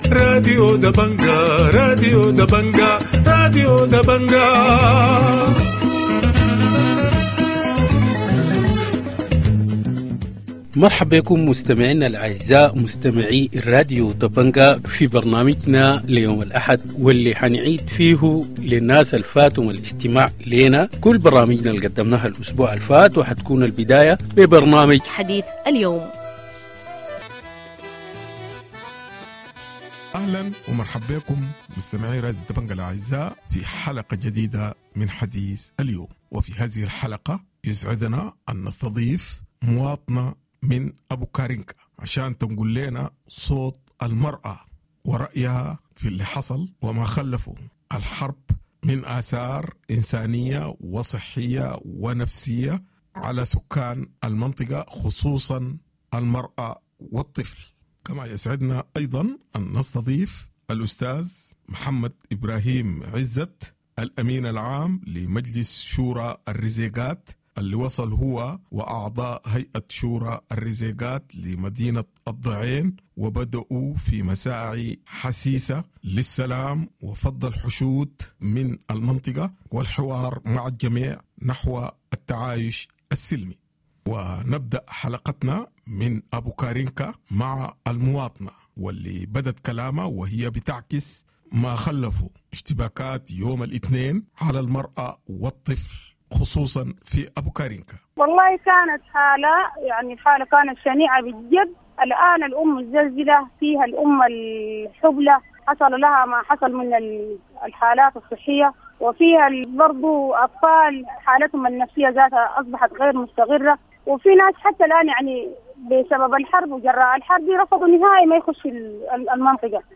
Weekend news review